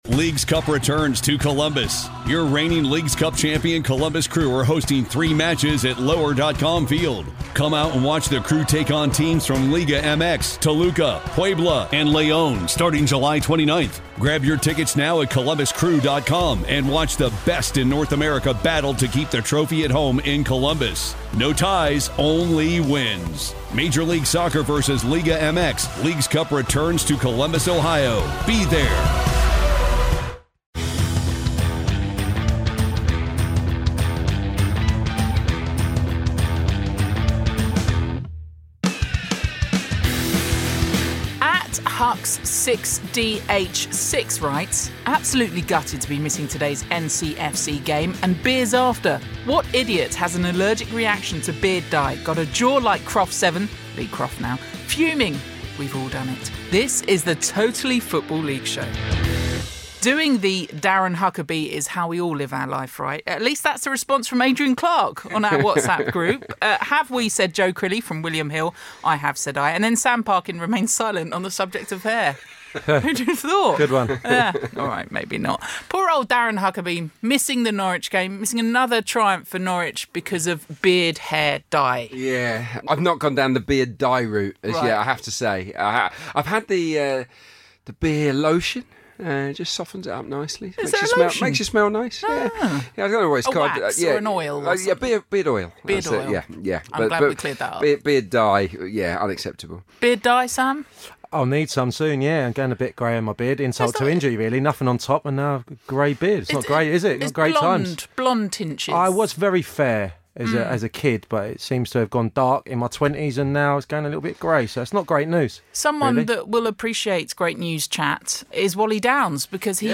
back in studio to discuss the latest managerial ins and outs, a full Championship review, plus an exclusive discussion with the makers of 'Sunderland Til I Die'.